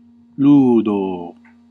Ääntäminen
Ääntäminen Classical: IPA: /ˈluː.doː/ Haettu sana löytyi näillä lähdekielillä: latina Käännöksiä ei löytynyt valitulle kohdekielelle.